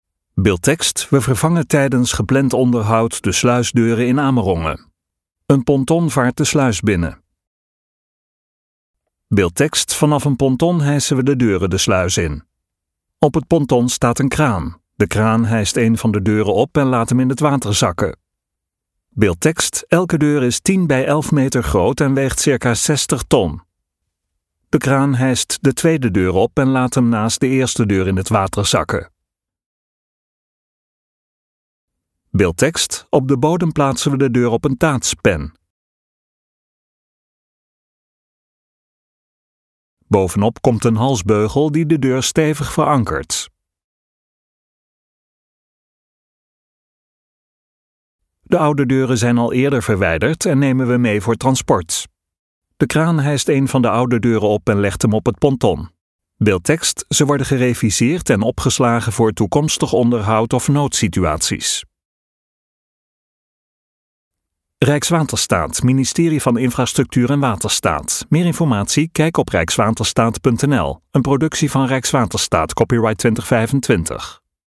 LEVENDIGE MUZIEK TOT HET EIND VAN DE VIDEO (Beeldtekst: Vanaf een ponton hijsen we de deuren de sluis in.